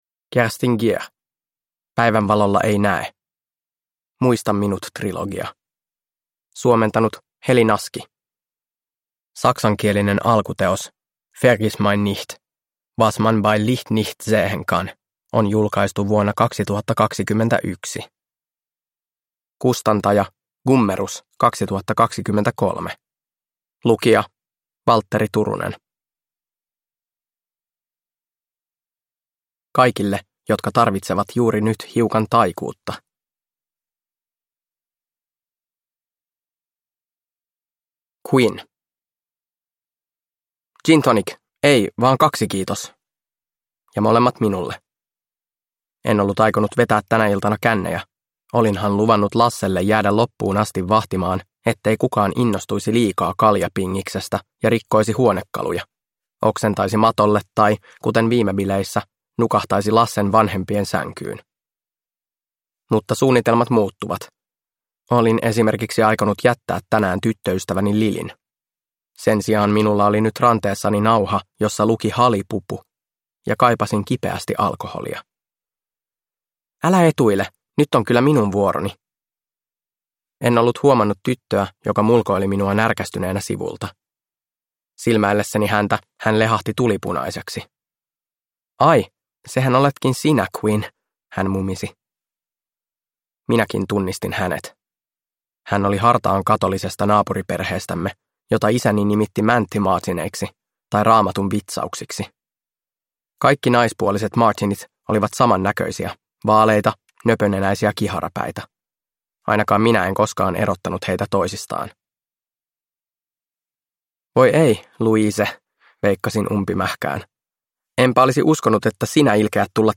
Päivänvalolla ei näe – Ljudbok – Laddas ner